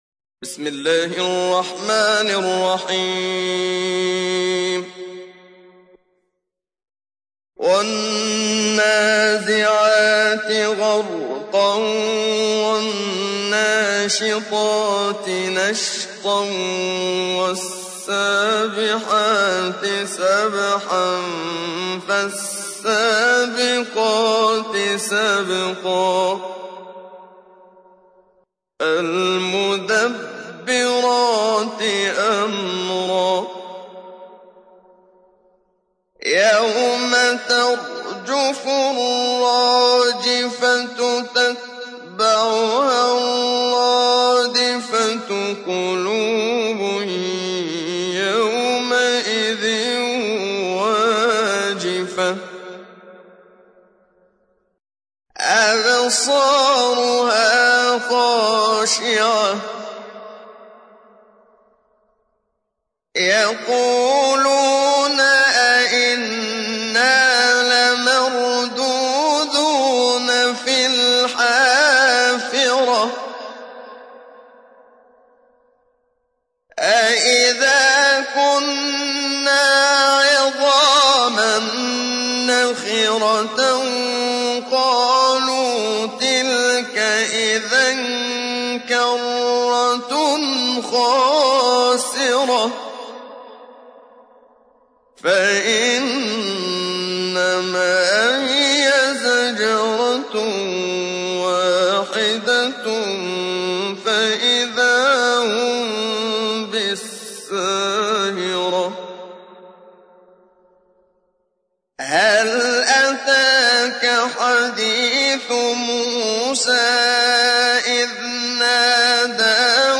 تحميل : 79. سورة النازعات / القارئ محمد صديق المنشاوي / القرآن الكريم / موقع يا حسين